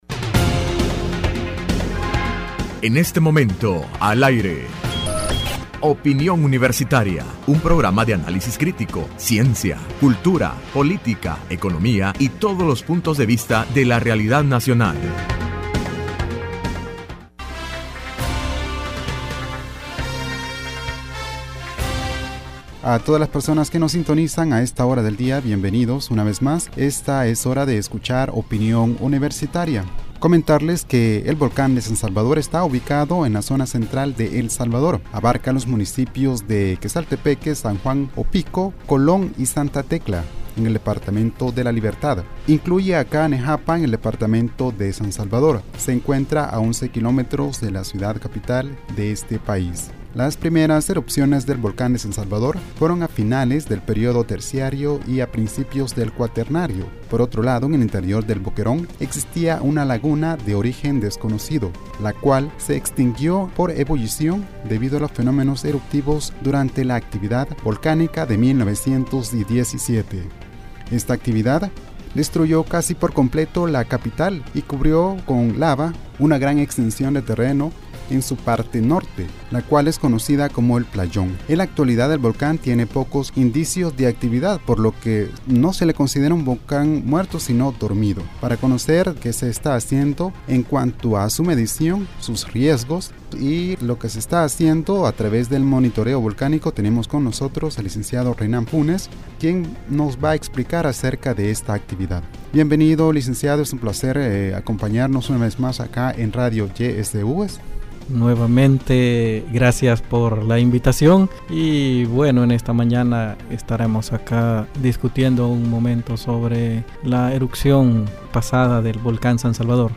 Entrevista Opinión Universitaria (10 de Agosto 2016) : Volcán de San Salvador, a casi cien años de su erupción.